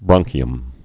(brŏngkē-əm)